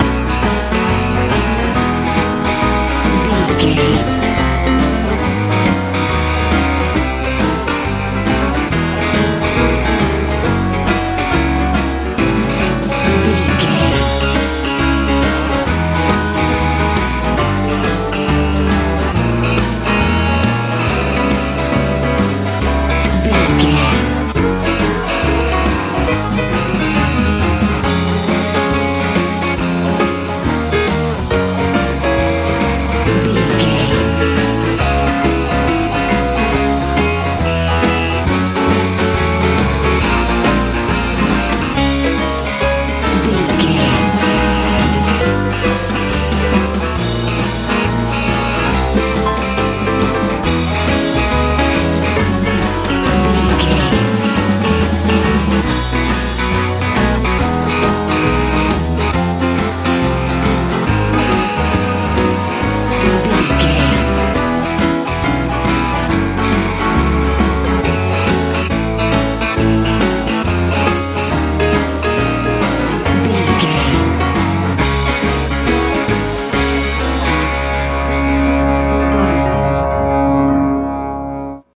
texas blues feel
Ionian/Major
groovy
funky
electric guitar
piano
bass guitar
drums
joyful
cheerful/happy